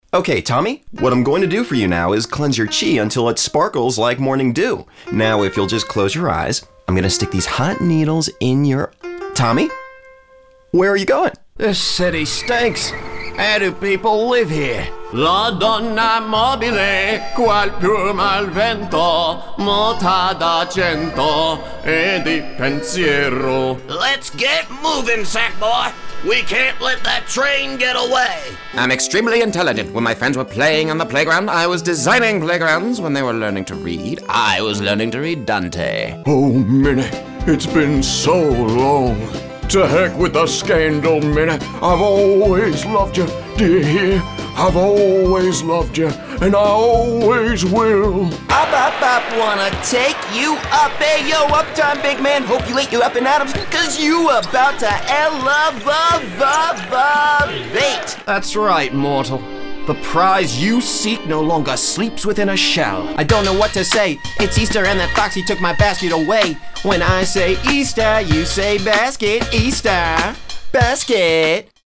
Young, Mid-Range Mid 20s-Mid 30s
Sprechprobe: Sonstiges (Muttersprache):